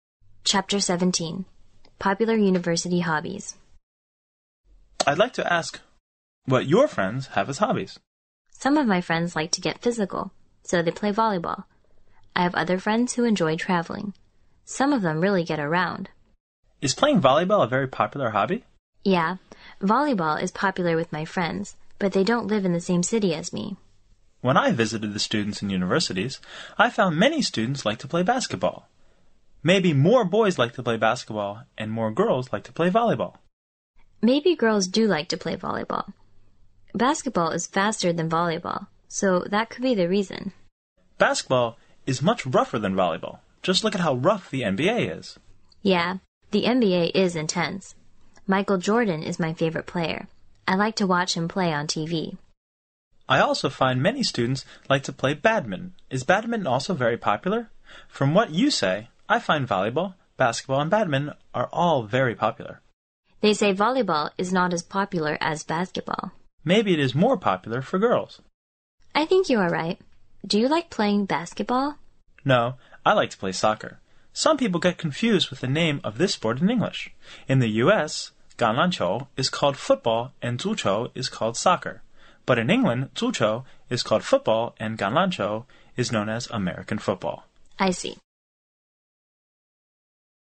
原汁原味的语言素材，习得口语的最佳语境。